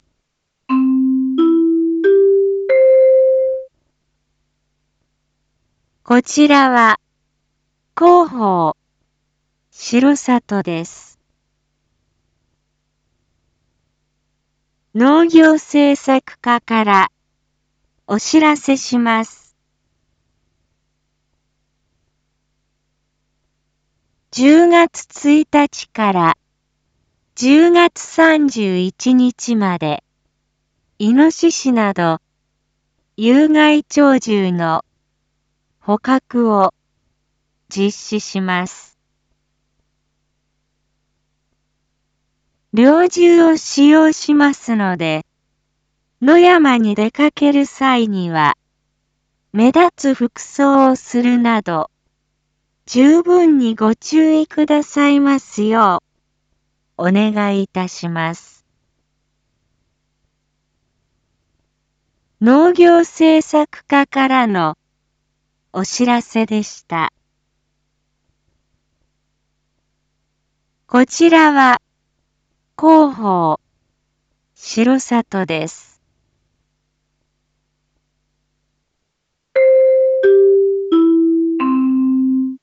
Back Home 一般放送情報 音声放送 再生 一般放送情報 登録日時：2023-10-08 19:01:24 タイトル：有害鳥獣捕獲について インフォメーション：こちらは、広報しろさとです。